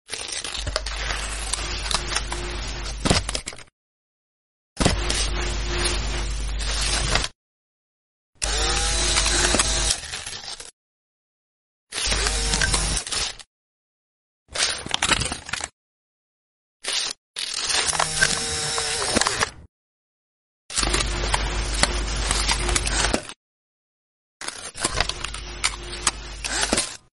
Infuse a gritty, old school feel sound effects free download
🖤 Packed with torn paper effects, handwritten touches, and matching sound effects, this set is ideal for creators aiming to capture a genuine retro film vibe.